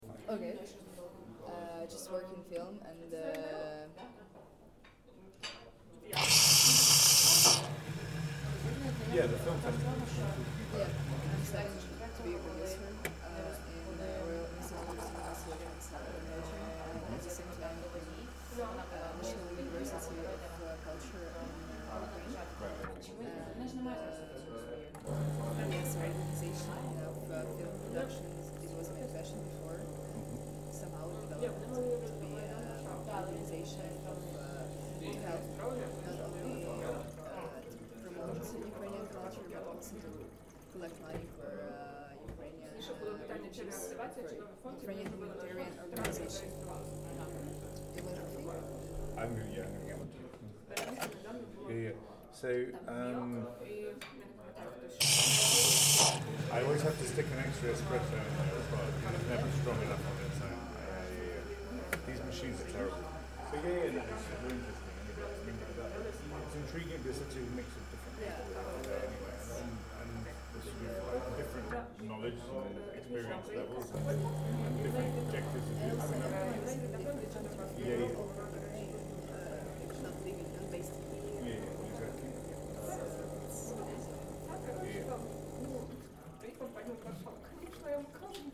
This recording - of coffees being made and some chit-chat during the coffee break - highlights that despite all of the horrors of the war, the violence and the geopolitical wranglings that make the headlines, there is a mundanity and ordinariness to the refugee experience.
Part of the Migration Sounds project, the world’s first collection of the sounds of human migration.